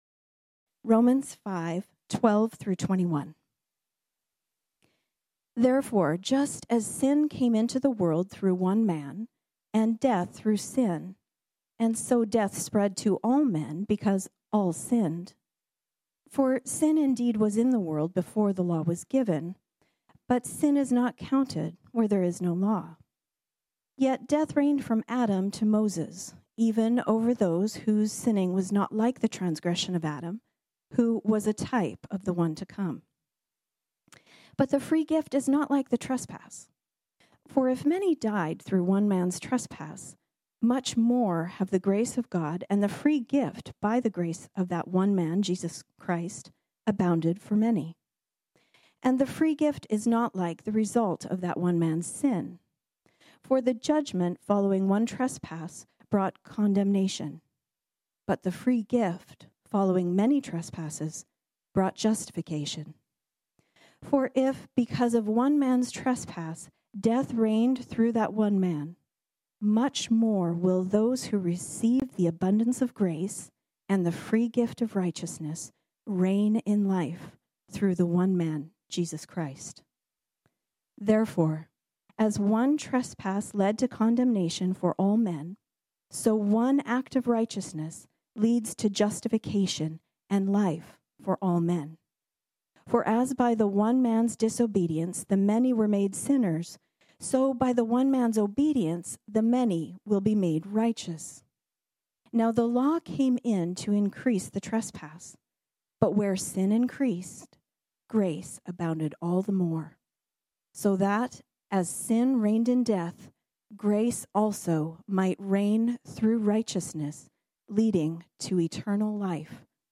This sermon was originally preached on Sunday, March 28, 2021.